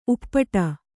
♪ uppḍu